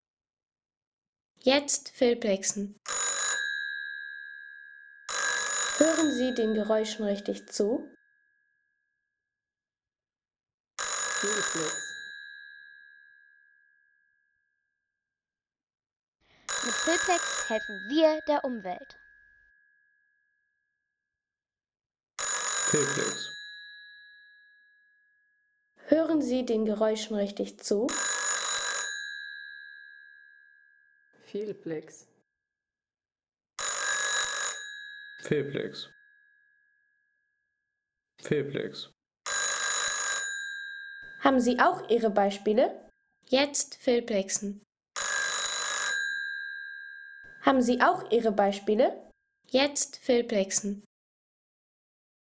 Klingelton FeTAp 611-2
Fernsprechtischapparat POST FeTAp 611-2 – Klassiker der 80er Jahre.